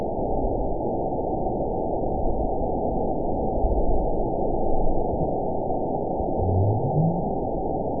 event 911451 date 02/28/22 time 19:46:11 GMT (3 years, 3 months ago) score 9.71 location TSS-AB01 detected by nrw target species NRW annotations +NRW Spectrogram: Frequency (kHz) vs. Time (s) audio not available .wav